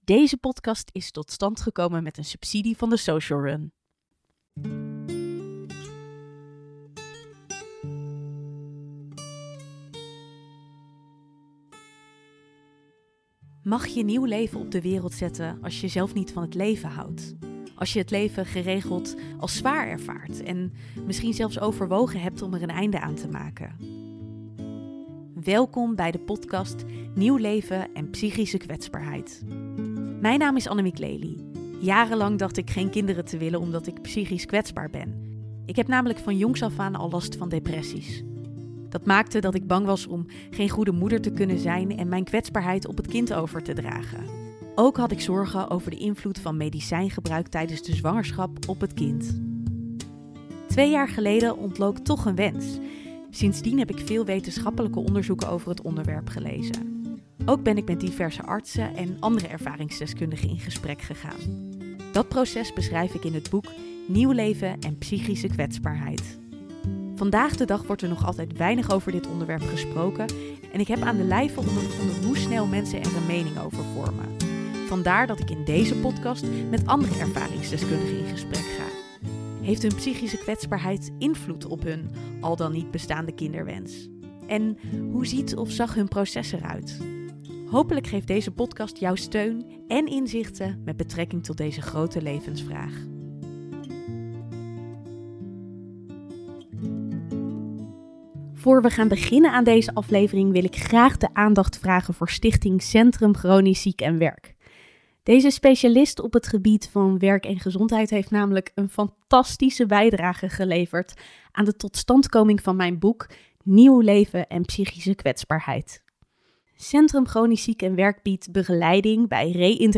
Een open gesprek!